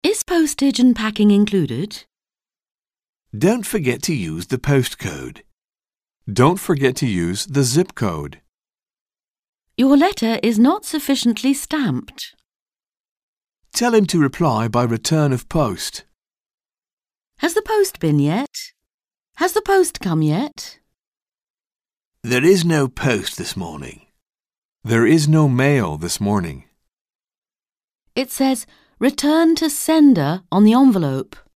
Un peu de conversation - Le courrier postal